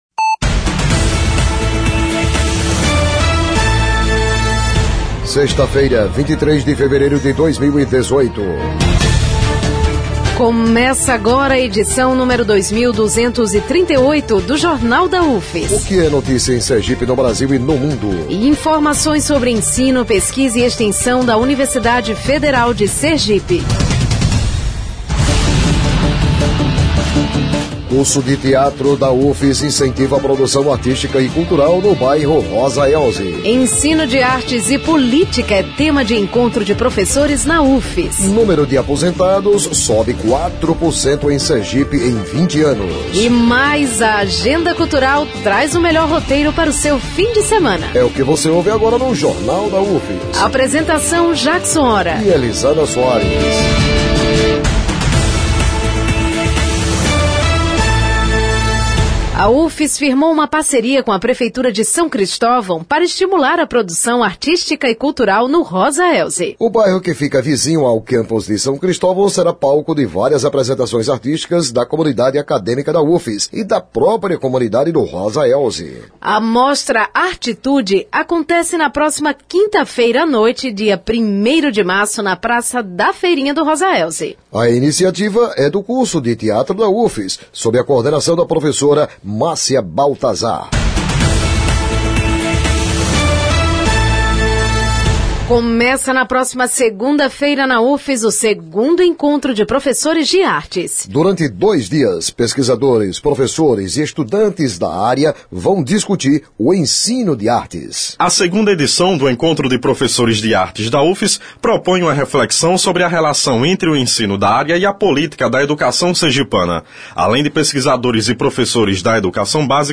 O noticiário vai ao ar às 11h00 na Rádio UFS FM 92.1, com reprises às 17h00 e 22h00.